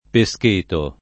pescheto [ pe S k % to ]